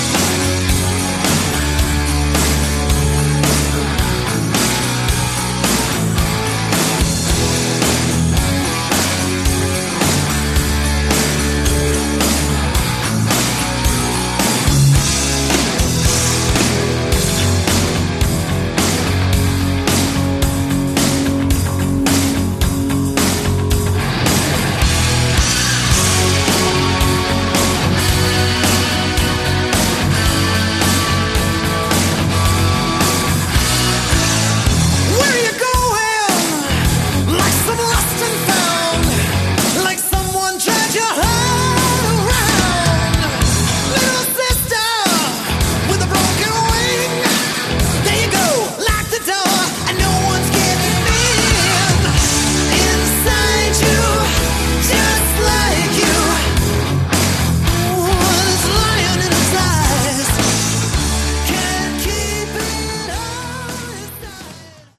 Category: Hard Rock
bass
guitars
vocals
drums, percussion
synthesizers